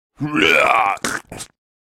owl-puke.ogg.mp3